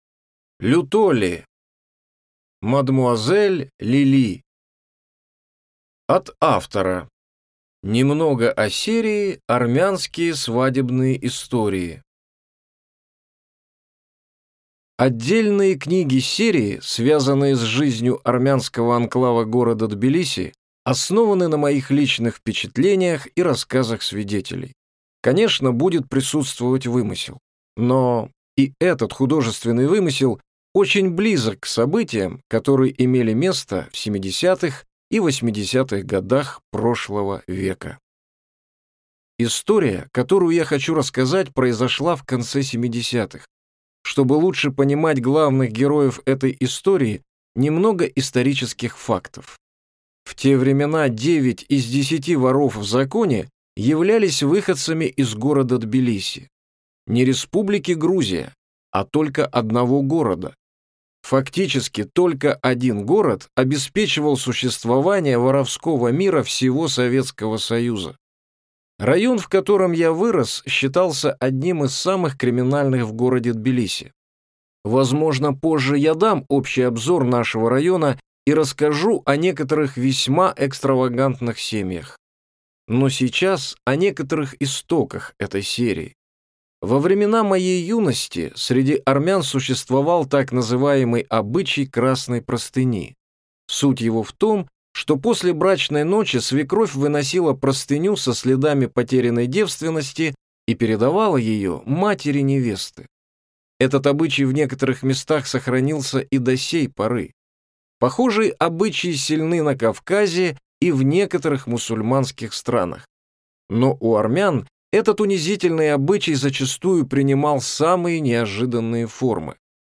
Аудиокнига Мадмуазель Лили | Библиотека аудиокниг